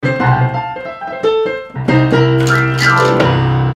Play, download and share Cool riff stuff original sound button!!!!
riff.mp3